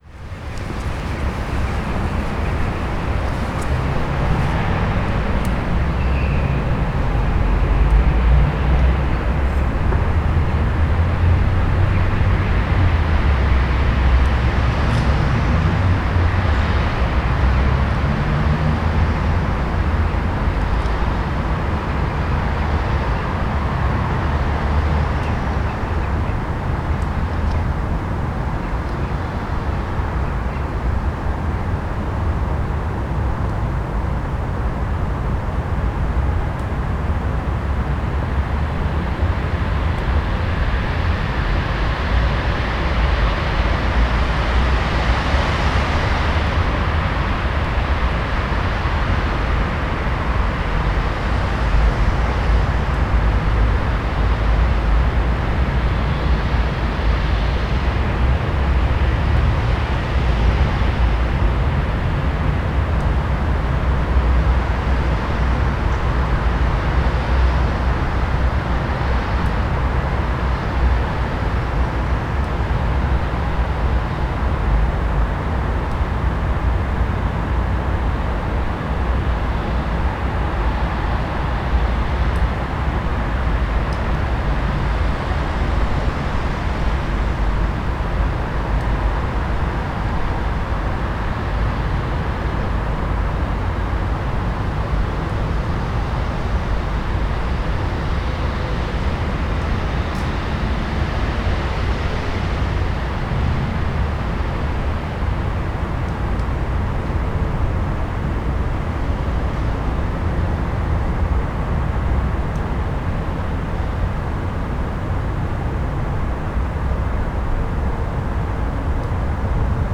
ambient-city.wav